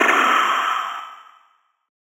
Gamer World FX 1.wav